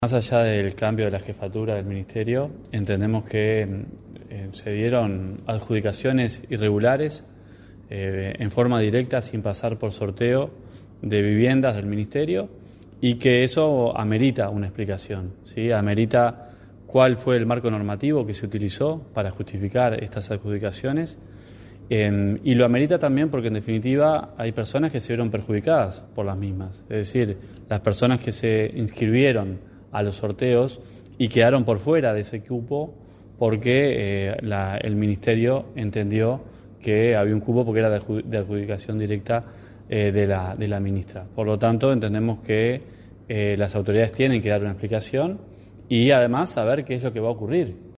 Escuche las declaraciones de Sebastián Sabini: